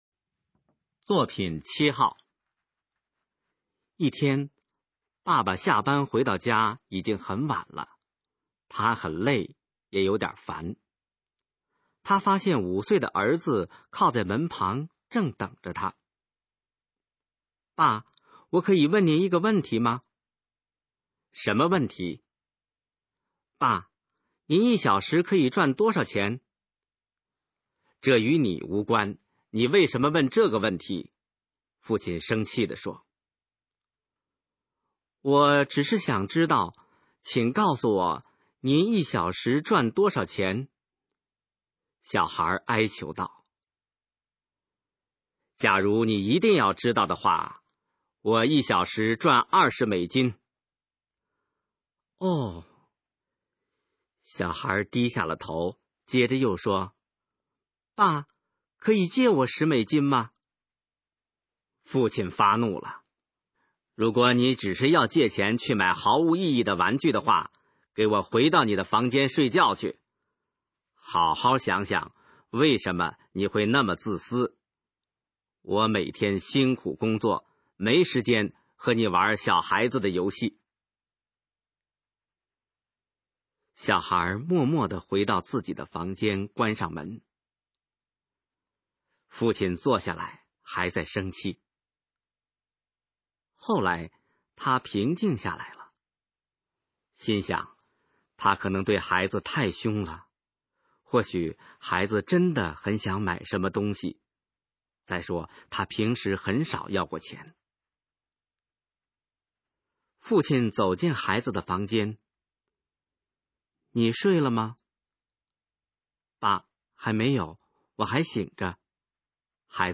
《二十美金的价值》示范朗读_水平测试（等级考试）用60篇朗读作品范读　/ 佚名